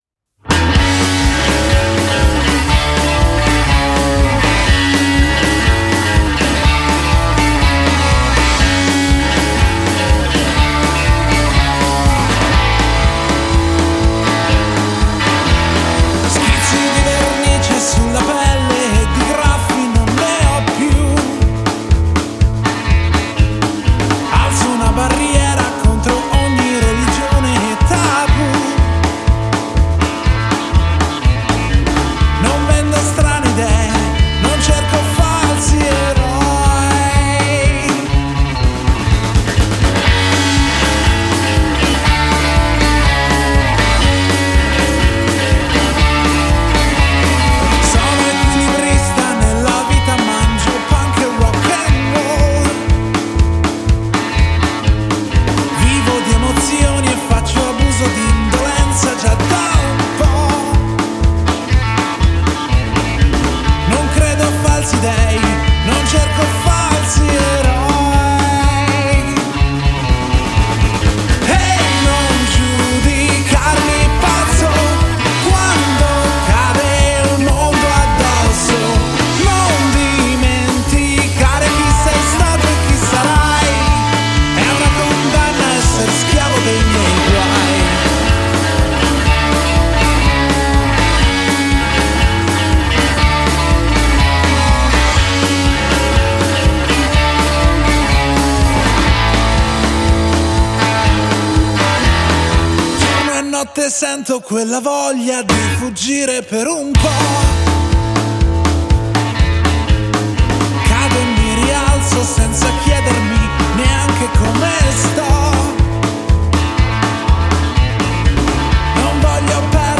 Genre : Rock'n'Roll